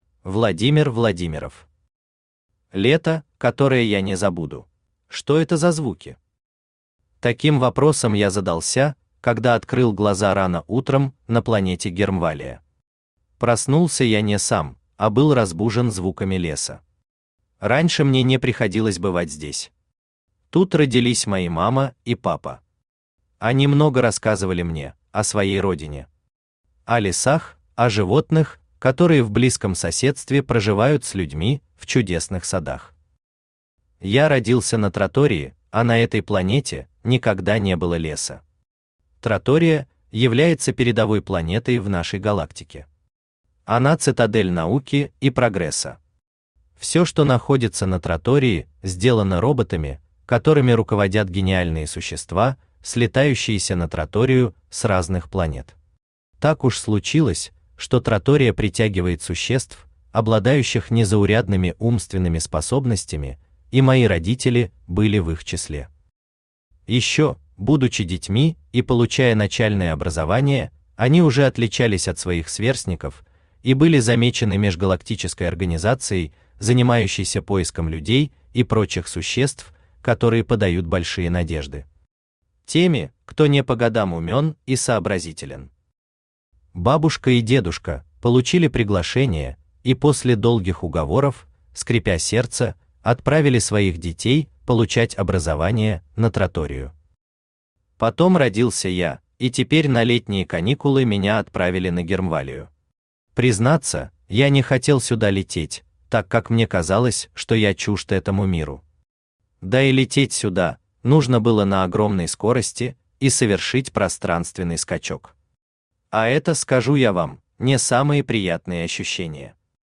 Аудиокнига Лето, которое я не забуду